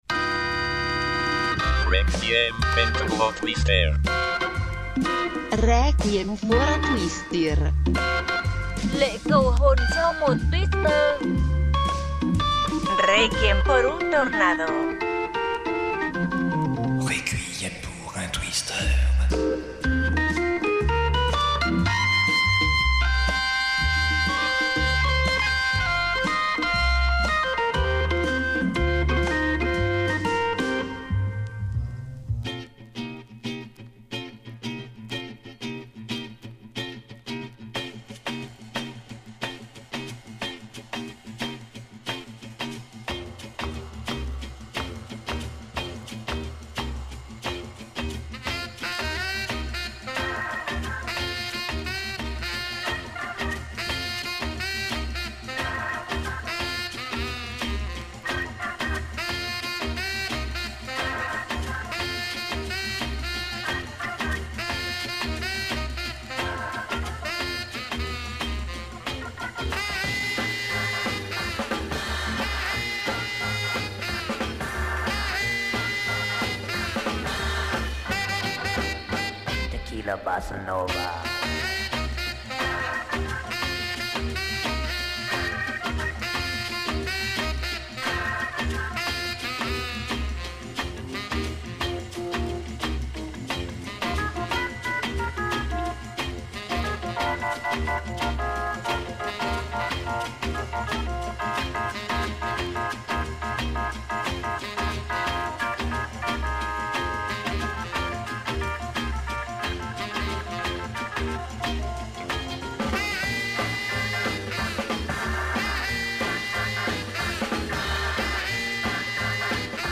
24 morceaux, 6 pays traversés, du très beau monde !
Mix Éclectique